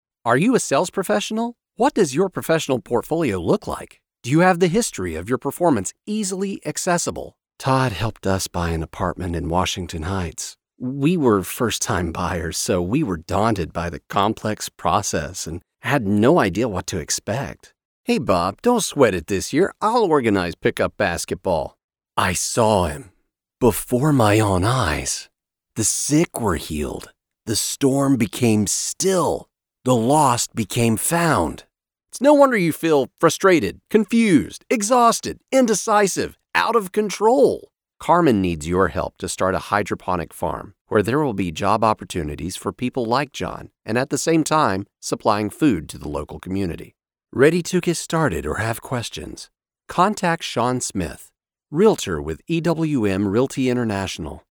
Popüler Sesler
American_Male_owe_1.mp3